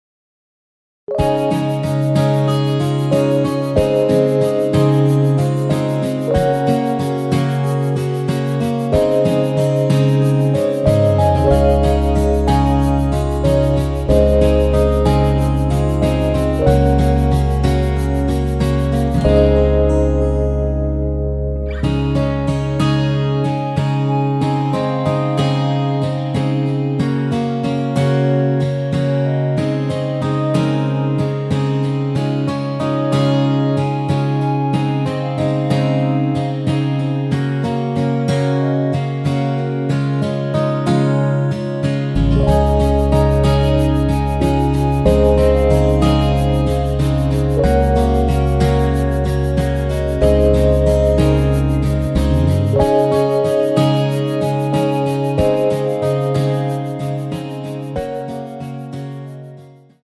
원키 멜로디 포함된 MR입니다.
Db
앞부분30초, 뒷부분30초씩 편집해서 올려 드리고 있습니다.
중간에 음이 끈어지고 다시 나오는 이유는